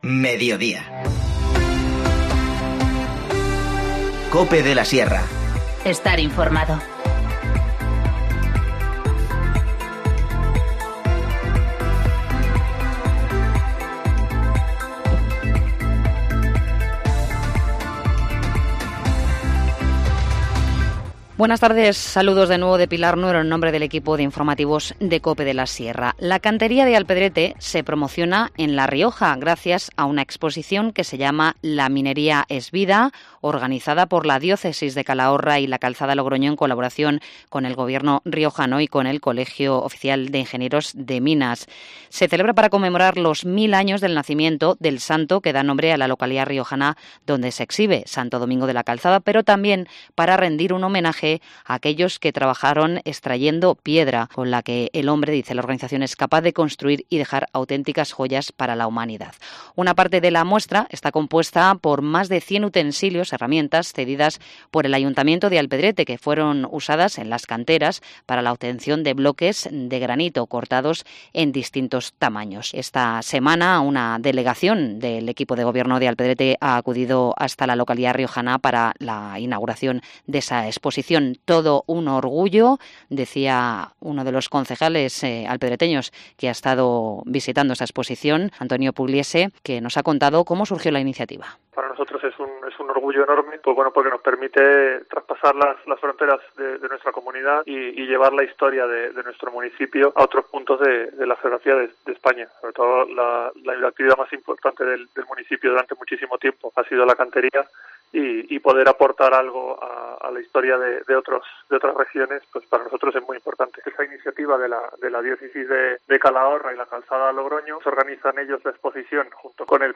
Informativo Mediodía 7 noviembre 14:50h